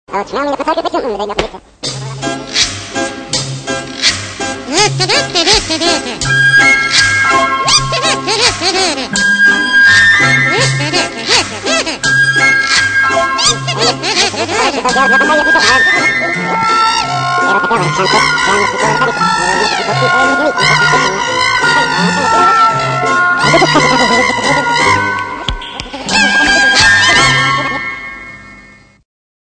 Категория: Саундтреки | Дата: 29.11.2012|